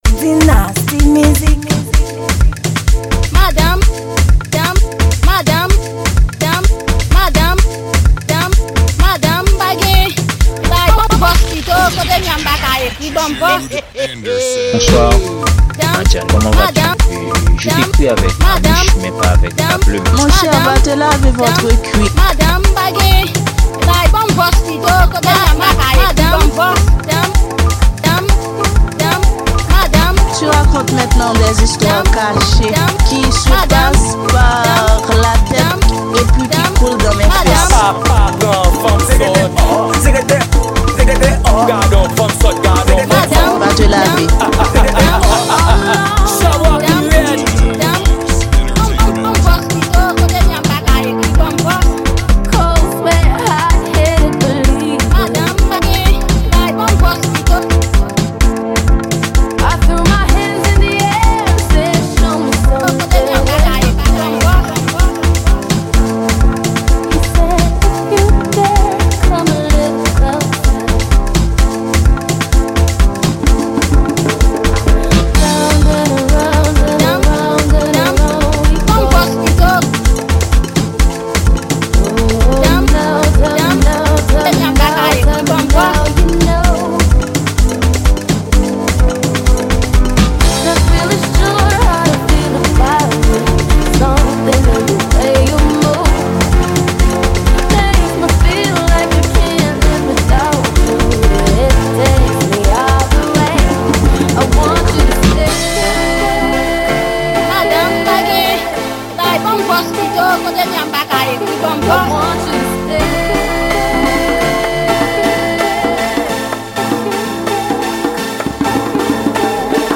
Genre: Remix.